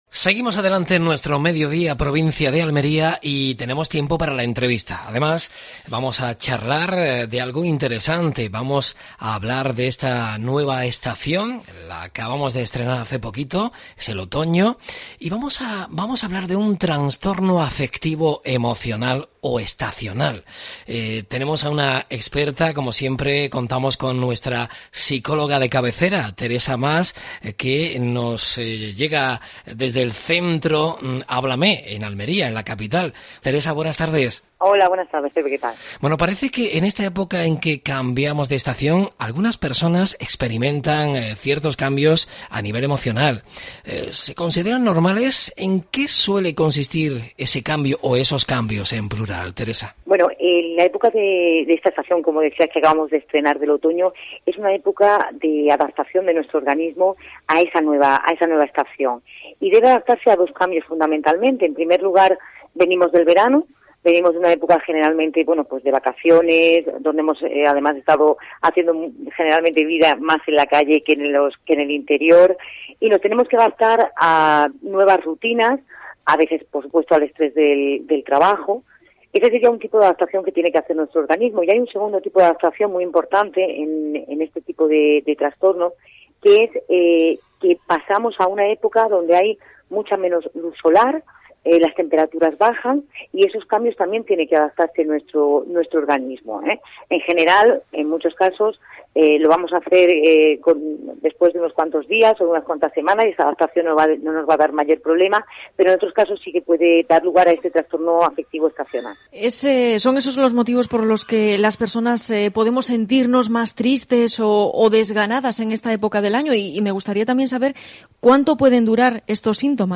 En otoño solemos notar cambios emocionales que pueden derivar en el trastorno afectivo estacional. Con una psicóloga analizamos los factores que influyen y las consecuencias.
Entrevista